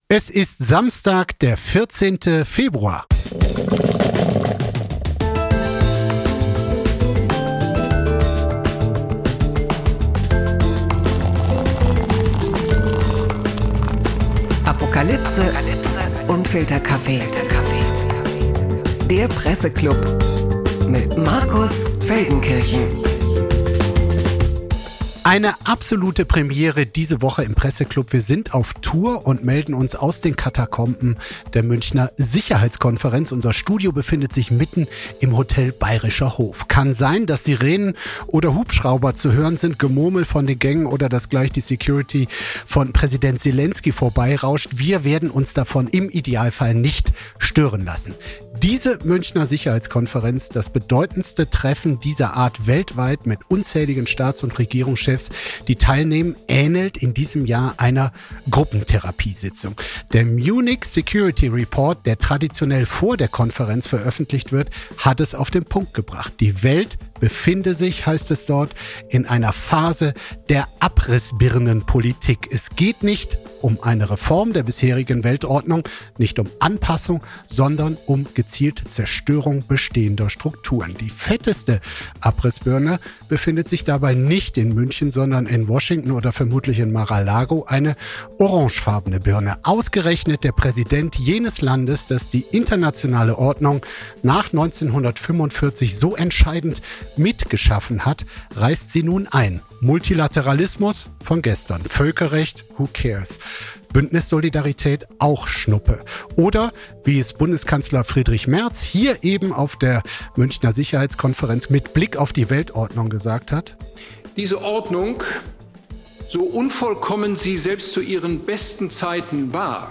Der Apofika-Presseklub live von der Münchber Sicherheitskonferenz - auf der Suche nach der neuen Weltordnung.